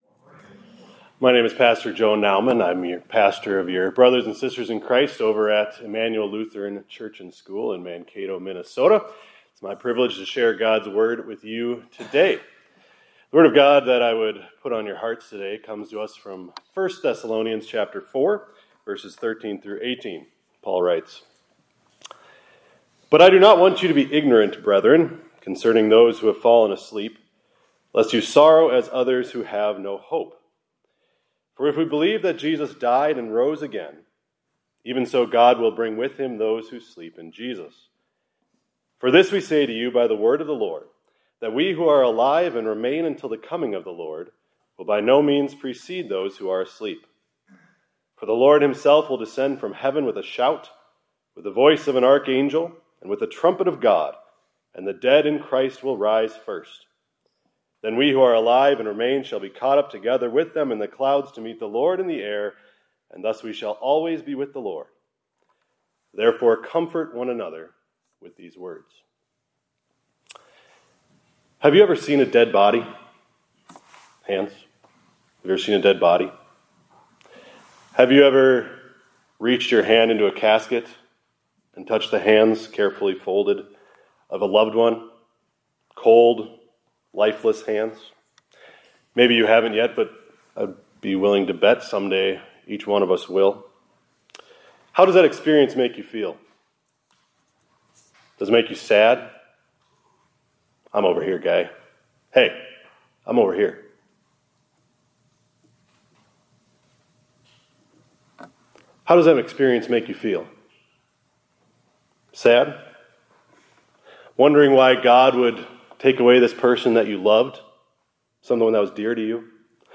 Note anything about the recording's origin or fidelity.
2026-04-15 ILC Chapel — Jesus Conquered Death